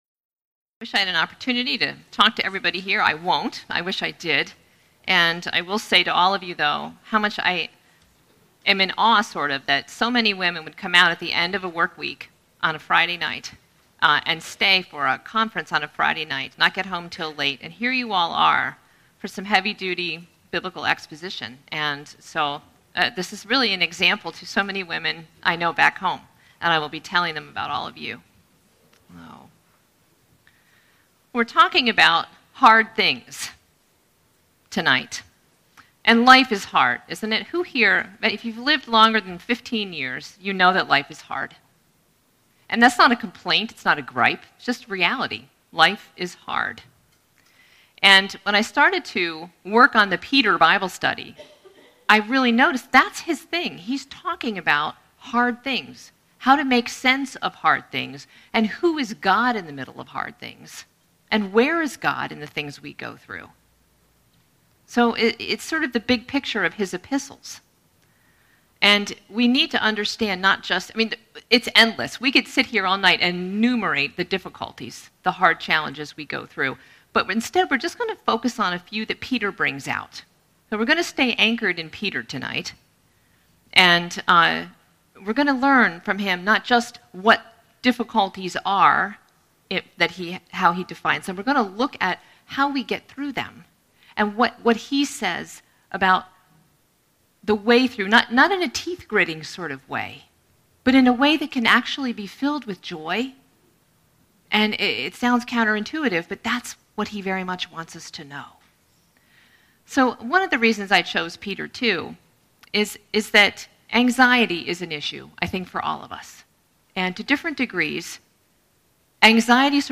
catch up on the talks from our BW Spring Conference 2020